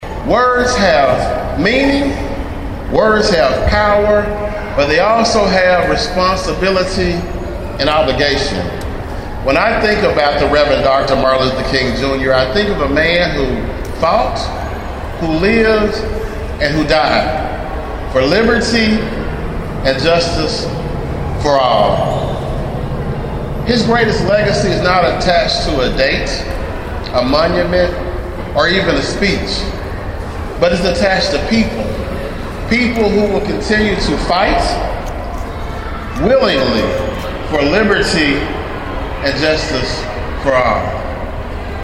A large crowd came to Discovery Park of America on Monday to take part in the annual celebration in remembrance of Dr. Martin Luther King Jr.